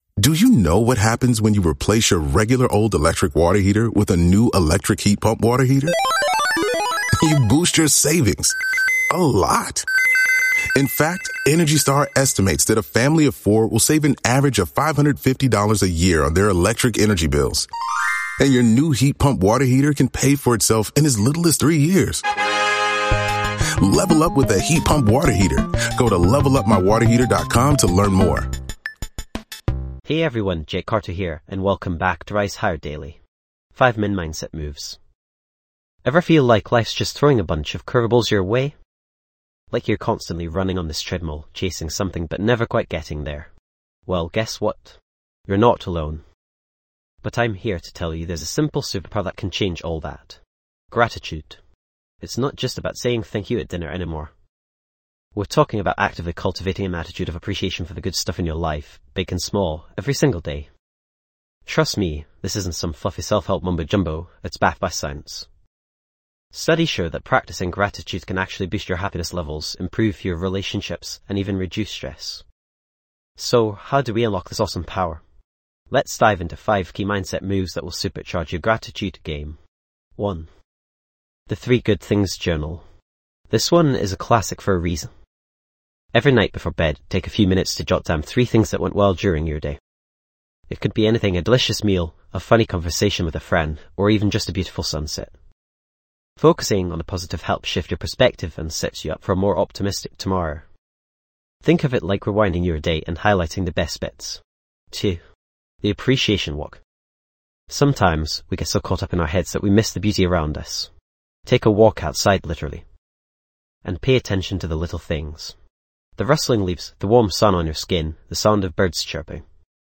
- Experience a guided gratitude meditation to help deepen your practice
This podcast is created with the help of advanced AI to deliver thoughtful affirmations and positive messages just for you.